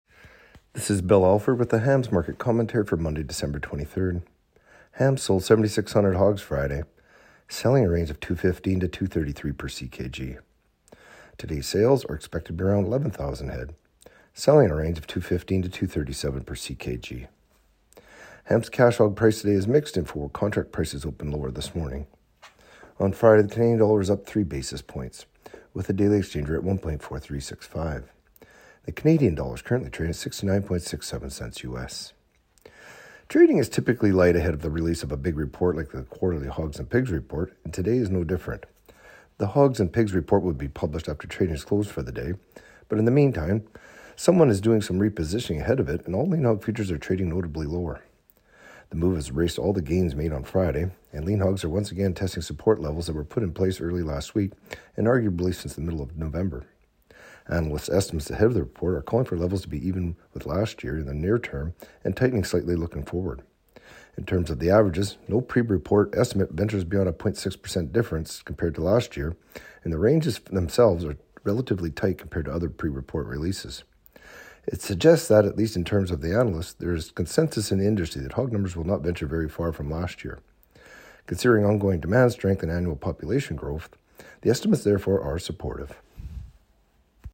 Market-Commentary-Dec.-23-24.mp3